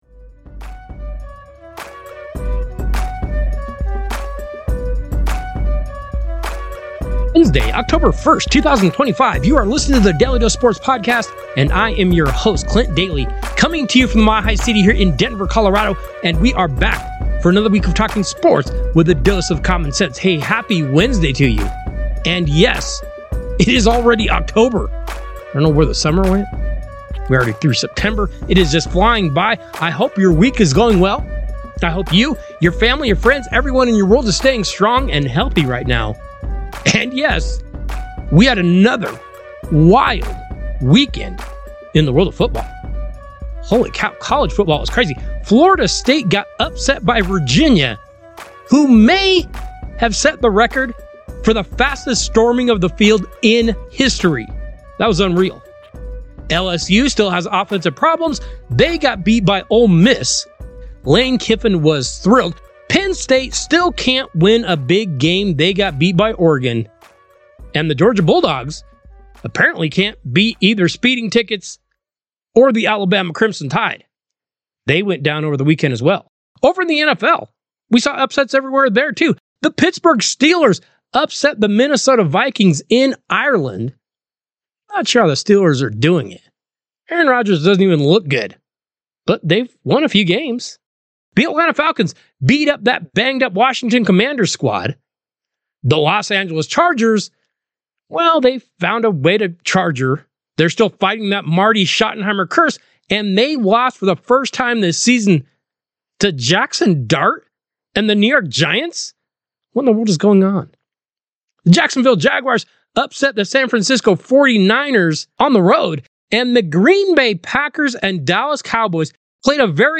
Daly Dose 10-01-25 Our interview with pro baseball player and agent